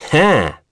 Esker-Vox_Happy4.wav